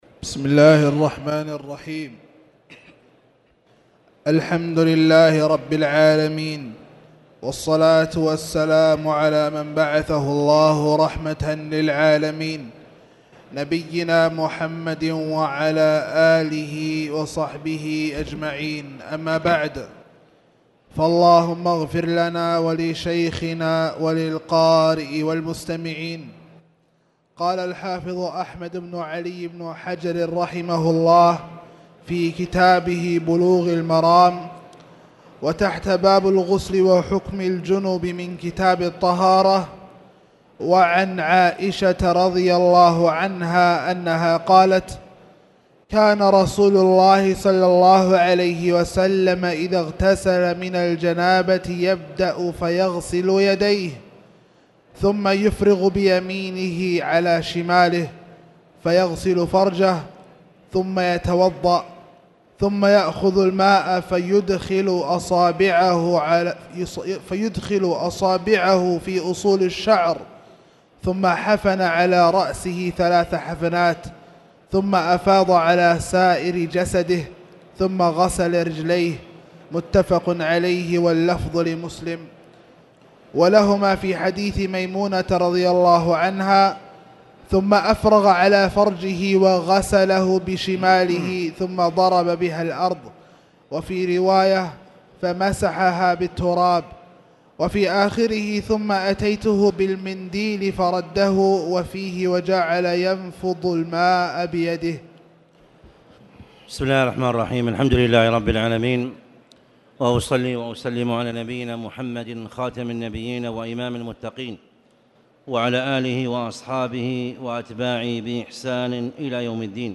تاريخ النشر ٢٢ ربيع الثاني ١٤٣٨ هـ المكان: المسجد الحرام الشيخ